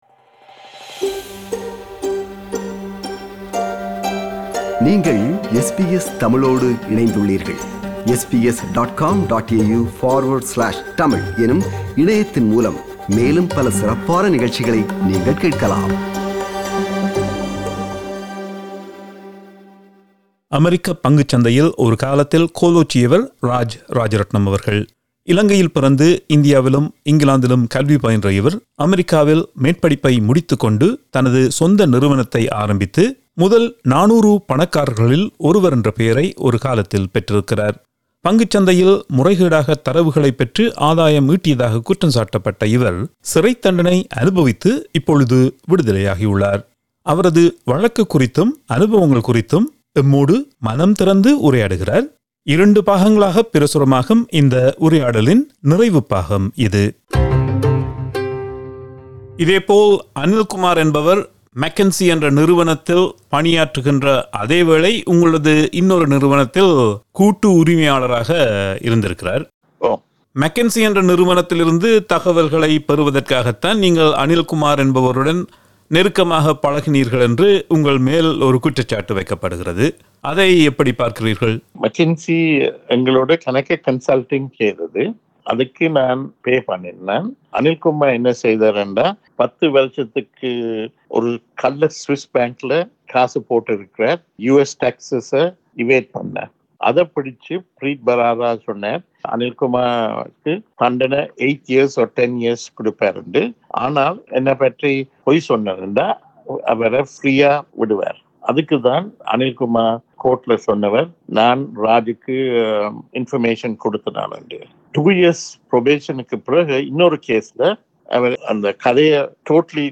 This is the final part of the two-part interview.